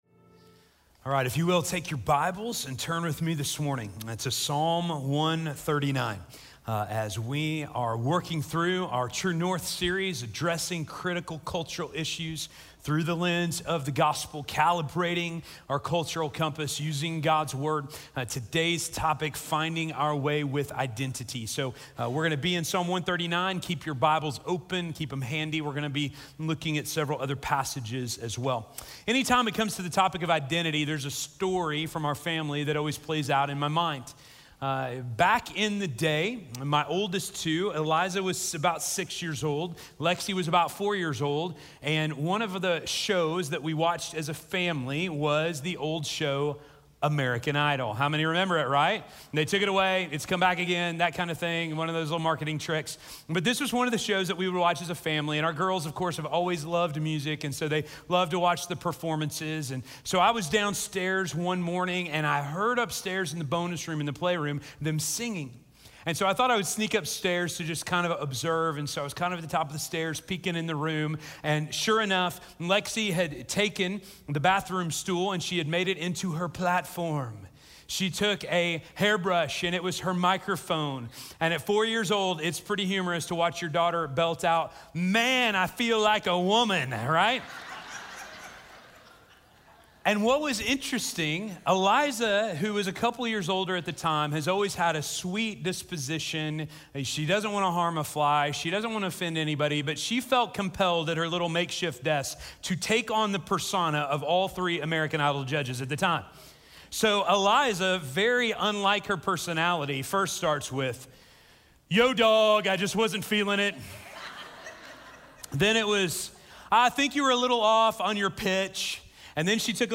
Finding Our Way with Identity - Sermon - Station Hill